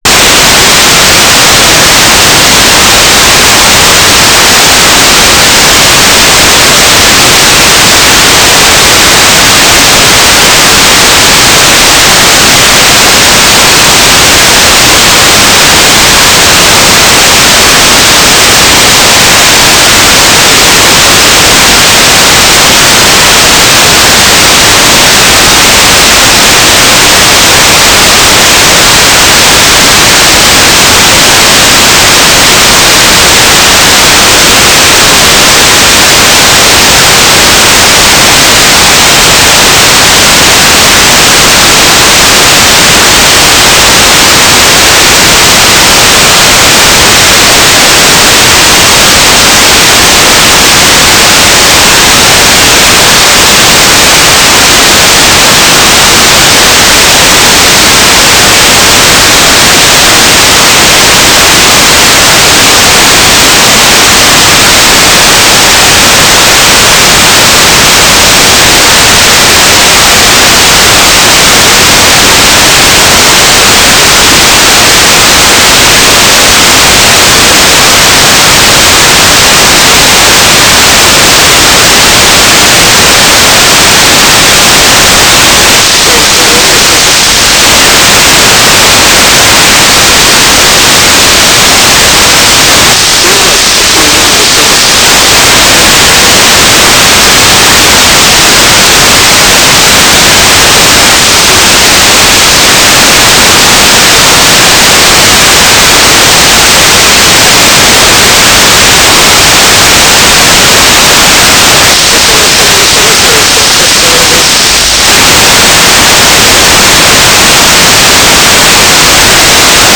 "transmitter_description": "Mode U - GMSK9k6 - AX.25 G3RUH - Telemetry",